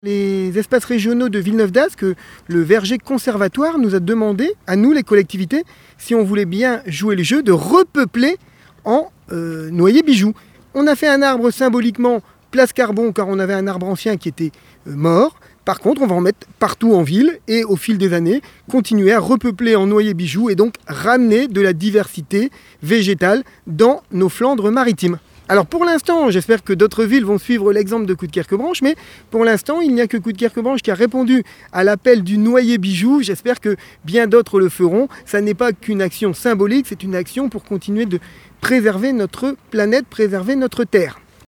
David Bailleul, maire de Coudekerque, a répondu à un appel (ITV DeltaFM du 08 janvier 2025) :